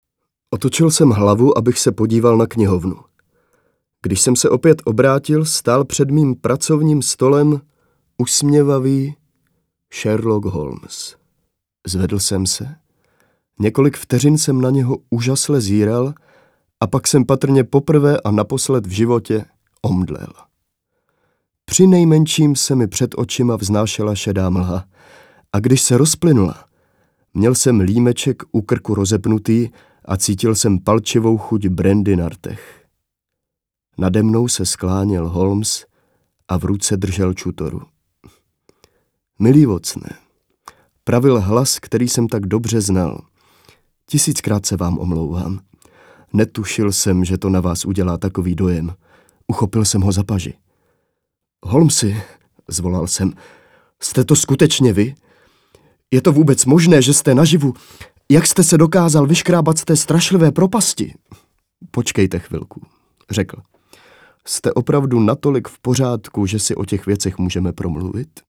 Audio knihy: